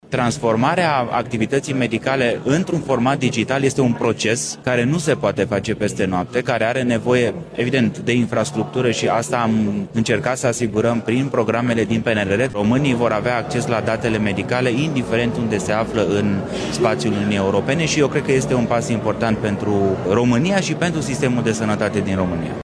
Ministrul Alexandru Rogobete a vorbit despre această platformă într-un interviu pentru Libertatea, acordat în cadrul Galei Doctorului Digital 2026.